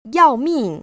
[yào//mìng] 야오밍